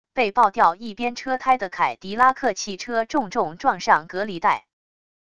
被爆掉一边车胎的凯迪拉克汽车重重撞上隔离带wav音频